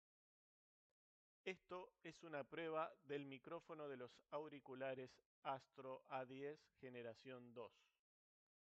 El A10 Gen 2 integra un micrófono unidireccional de 6,0 mm del tipo flexible y plegable ya que se puede voltear hacia arriba para silenciarlo.
Por otro lado el micrófono cumple bien su función, con una voz clara y definida, aquí les comparto una grabación para que tomen en cuenta:
Prueba-Mic-Astro-A10-Gen-2.mp3